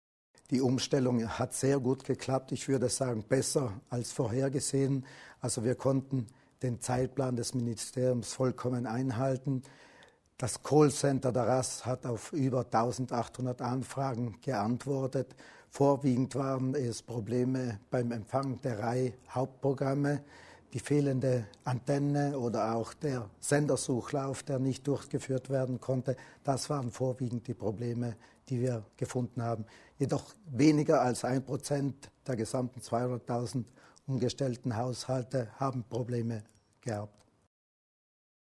O - Ton